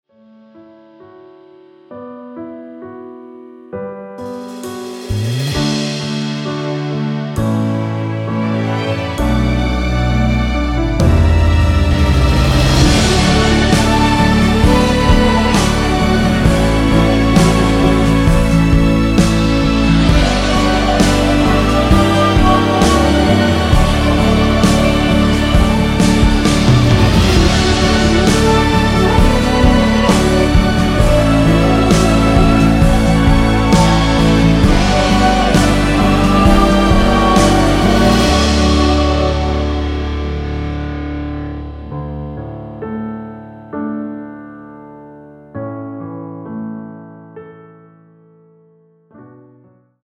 미리듣기에 나오는 부분이 코러스로 추가되었습니다.
(다른 부분은 원곡 자체에 코러스가 없습니다.)
원키에서(-5)내린 코러스 포함된 MR입니다.
앞부분30초, 뒷부분30초씩 편집해서 올려 드리고 있습니다.
중간에 음이 끈어지고 다시 나오는 이유는